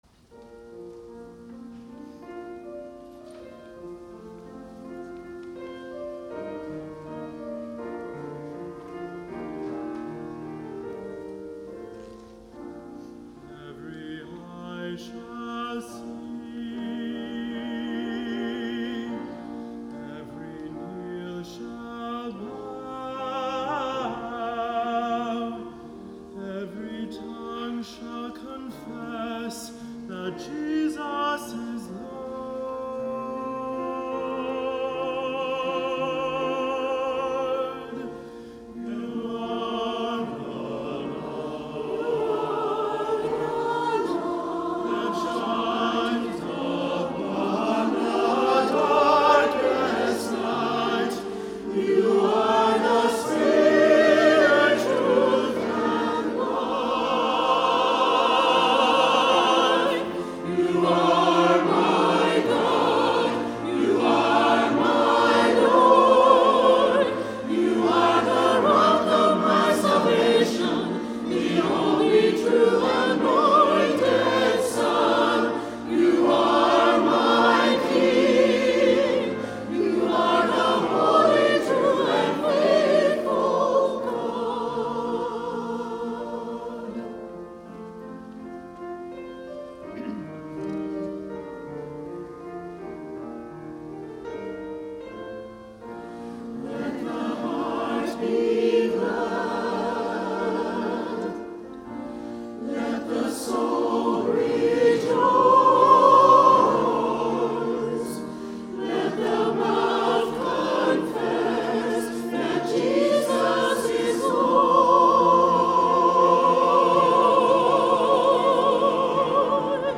Children’s Sabbath 2018 – 9:30